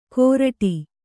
♪ kōraṭi